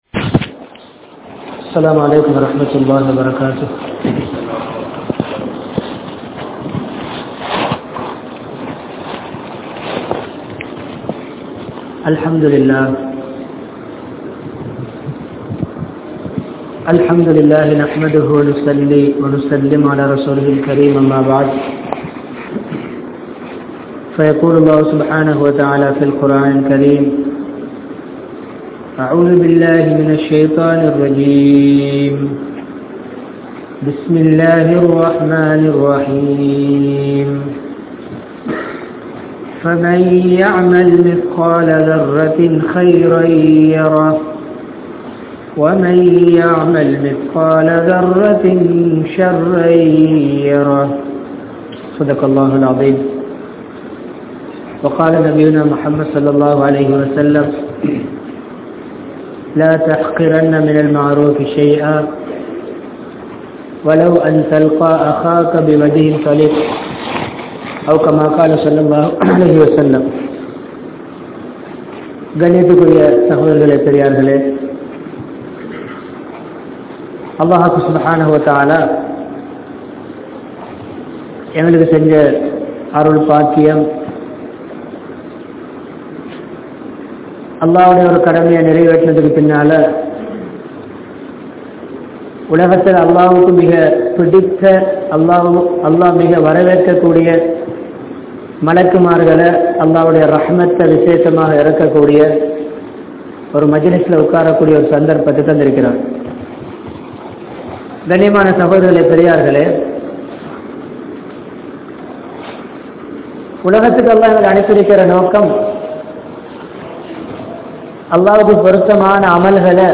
Amalhalin Perumathi (அமல்களின் பெறுமதி) | Audio Bayans | All Ceylon Muslim Youth Community | Addalaichenai
Malwana, Raxapana Jumua Masjidh